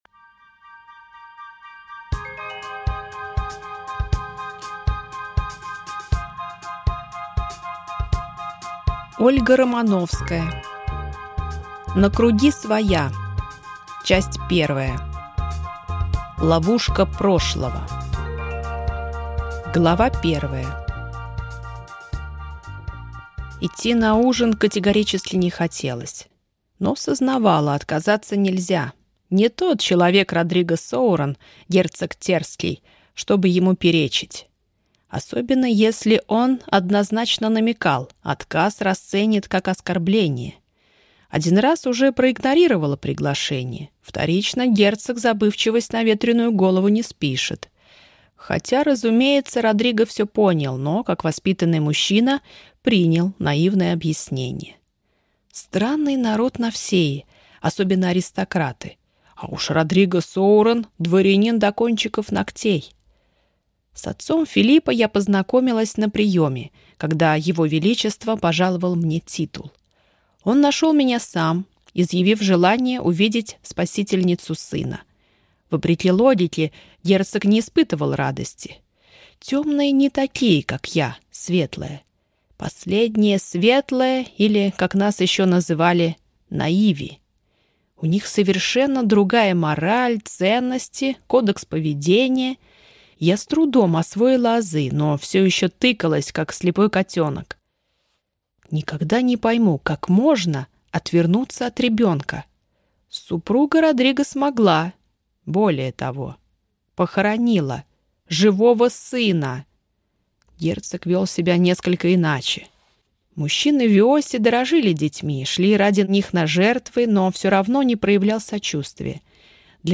Аудиокнига На круги своя. Часть 1. Ловушка прошлого | Библиотека аудиокниг